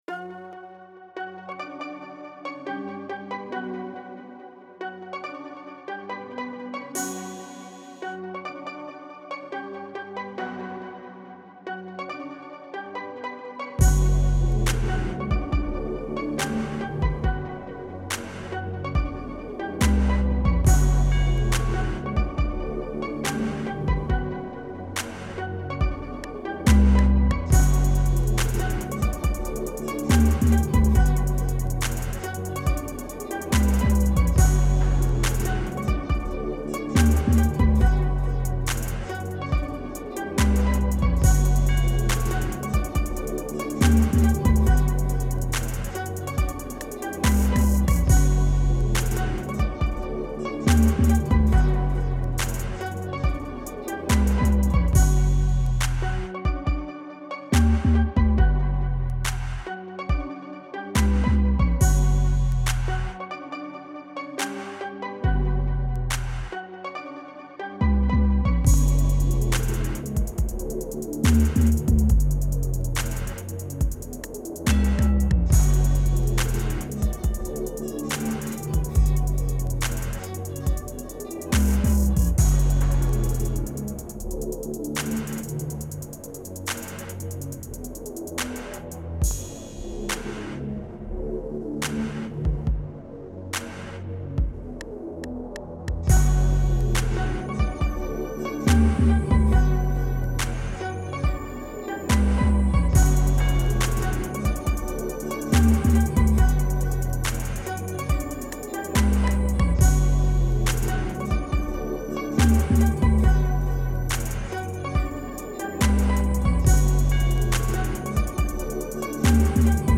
steel drums type trap/hiphop beat